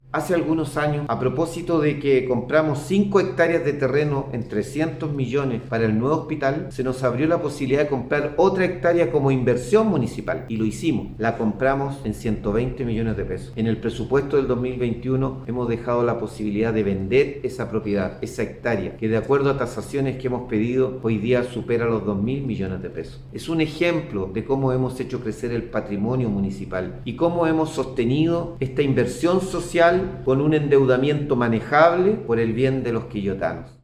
04-ALCALDE-MELLA-Venta-de-terreno.mp3